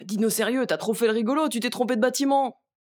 VO_LVL1_EVENT_Mauvais batiment_01.ogg